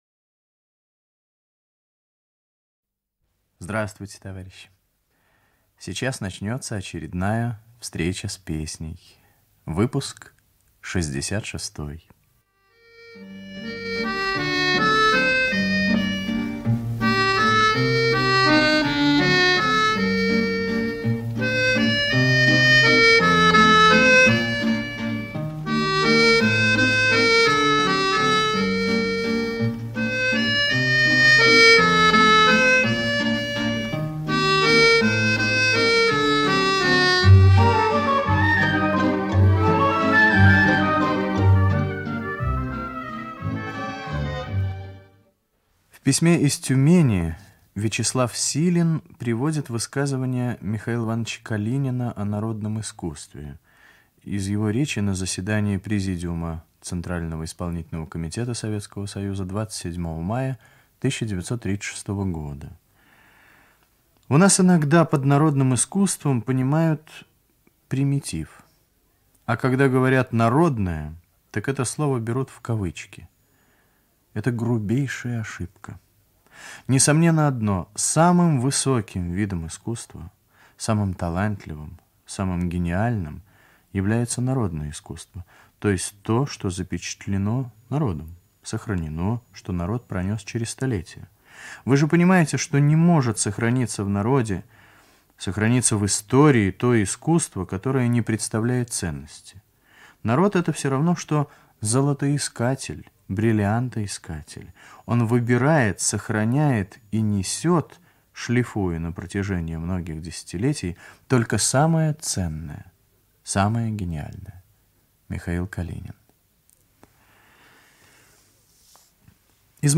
Радиопередача "Встреча с песней" Выпуск 66